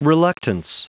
Prononciation du mot reluctance en anglais (fichier audio)
Prononciation du mot : reluctance